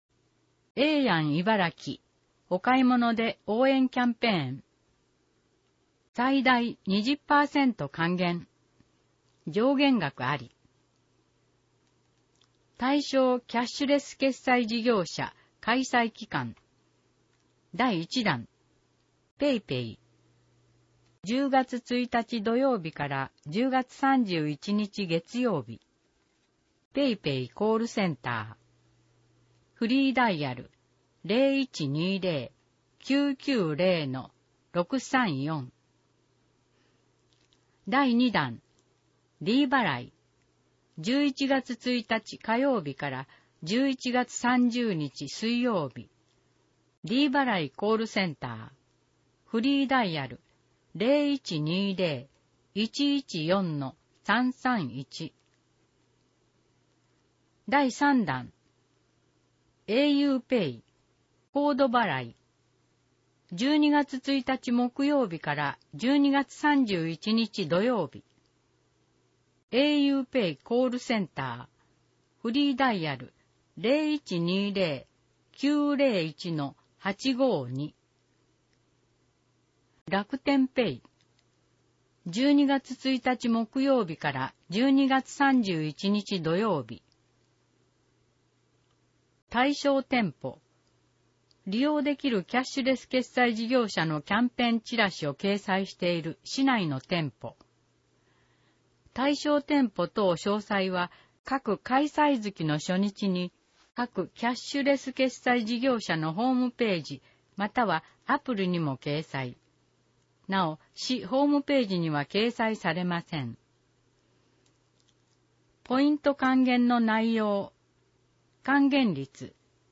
毎月1日発行の広報いばらきの内容を音声で収録した「声の広報いばらき」を聞くことができます。